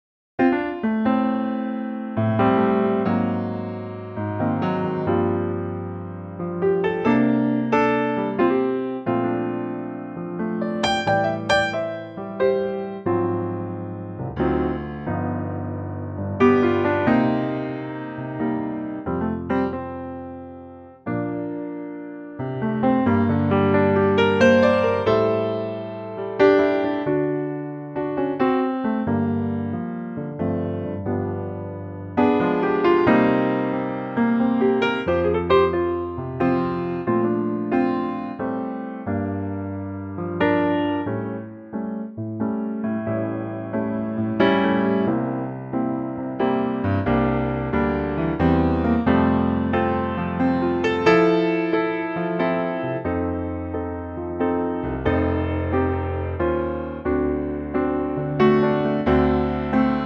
key - Bb - vocal range - A to D
Lovely piano only arrangement